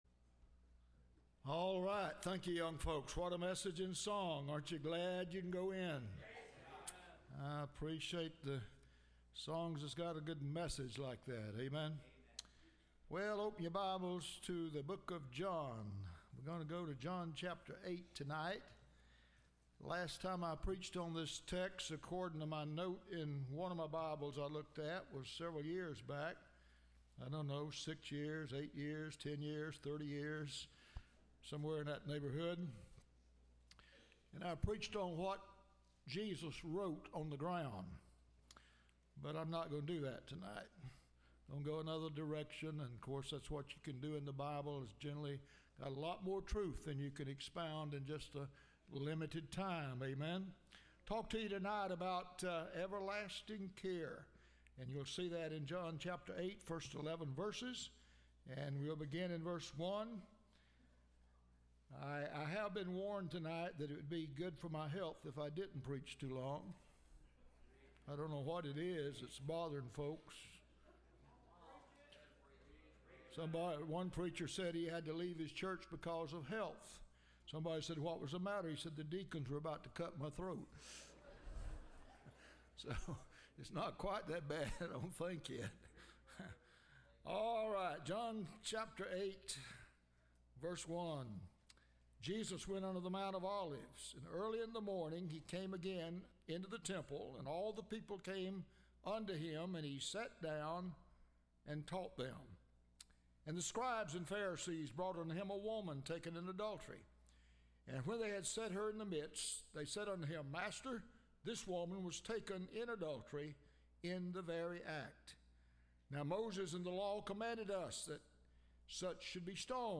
Listen to Message
Service Type: Sunday Evening